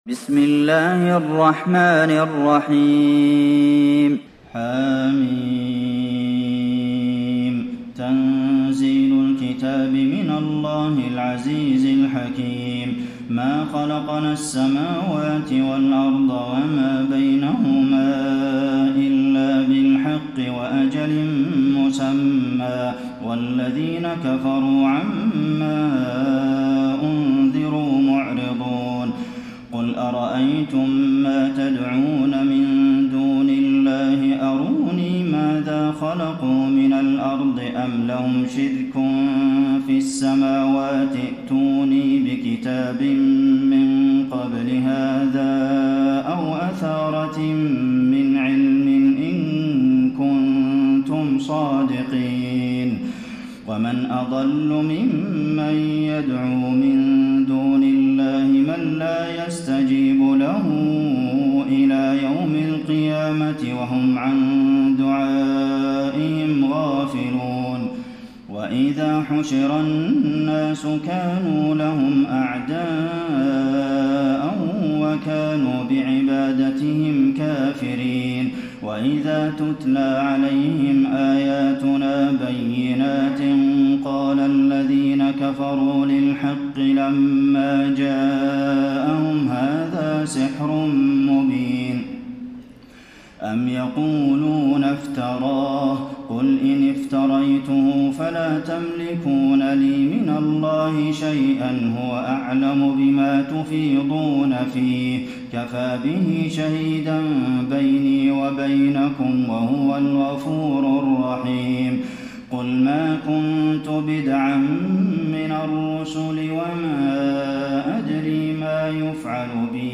تراويح ليلة 25 رمضان 1433هـ من سور الأحقاف و محمد والفتح (1-17) Taraweeh 25 st night Ramadan 1433H from Surah Al-Ahqaf and Muhammad and Al-Fath > تراويح الحرم النبوي عام 1433 🕌 > التراويح - تلاوات الحرمين